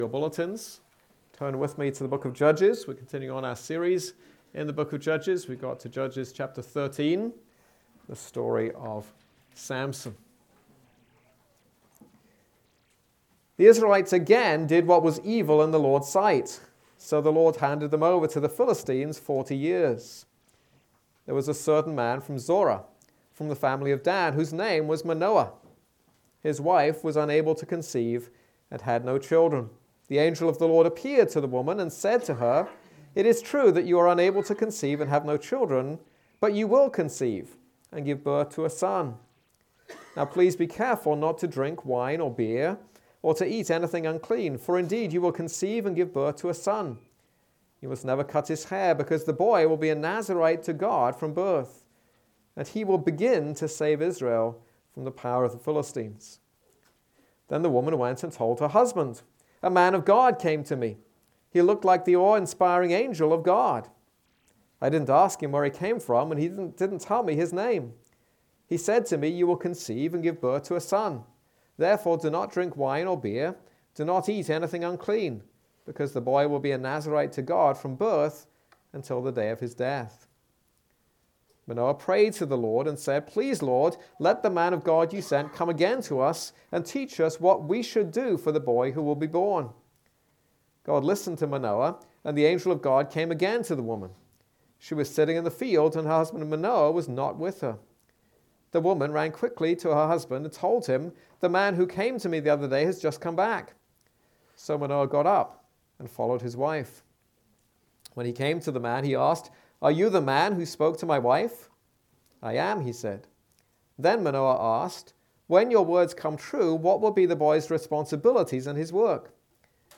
This is a sermon on Judges 13.